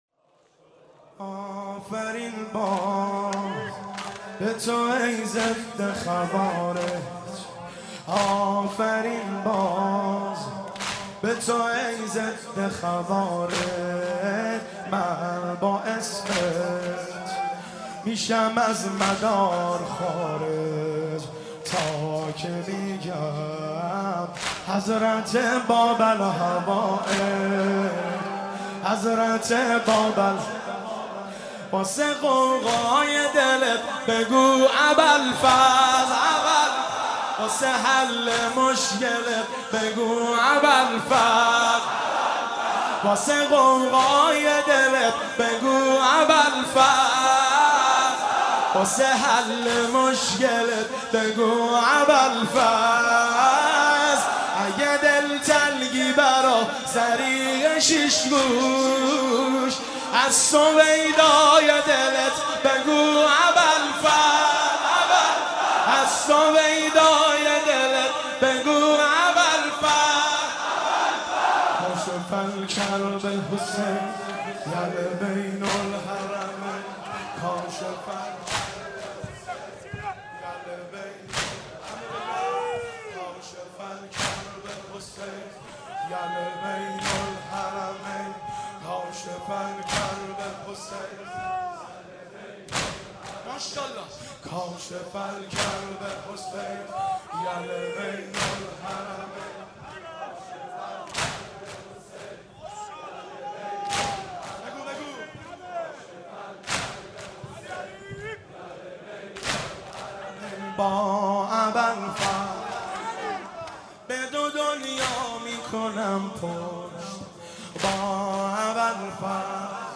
شب تاسوعا محرم 1392